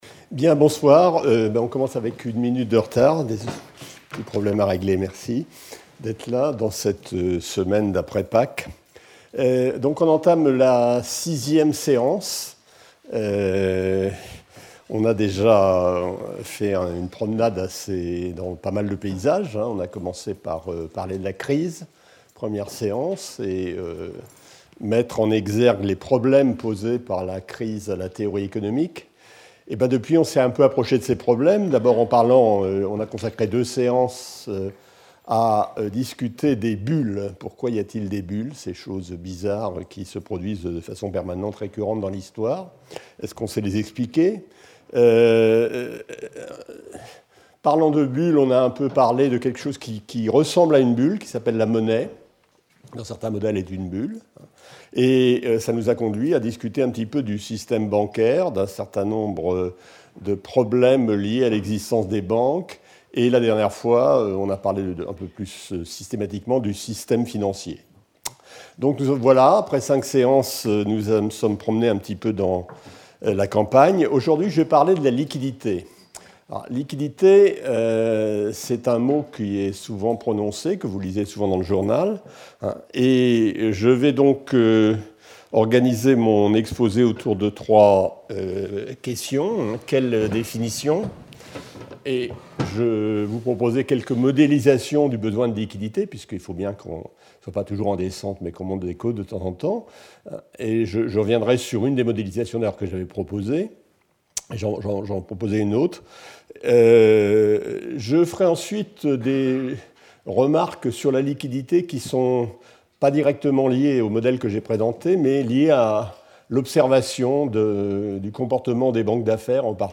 Speaker(s) Roger Guesnerie Professor at the Collège de France
Lecture